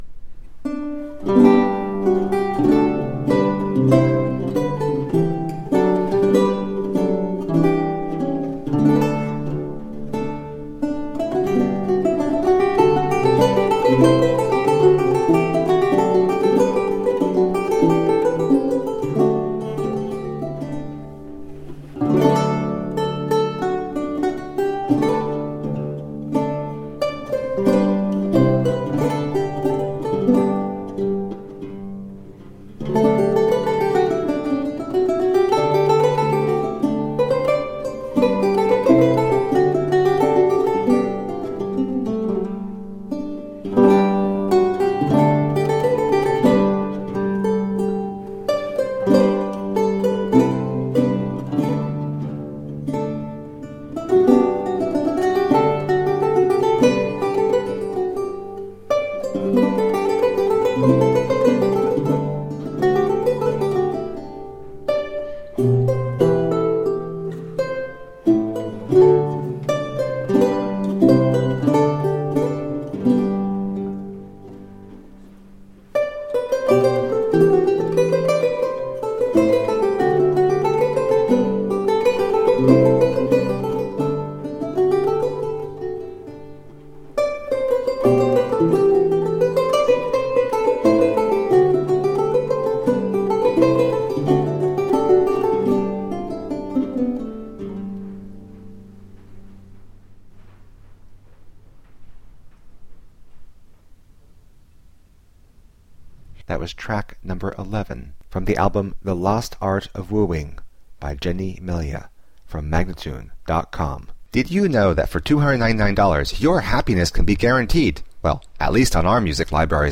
Elizabethan lute and folk song.
Classical, Renaissance, Classical Singing
Lute